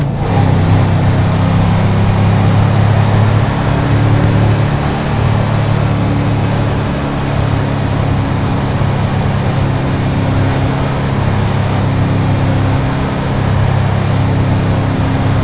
Index of /server/sound/vehicles/tdmcars/gtav/mesa3
fourth_cruise.wav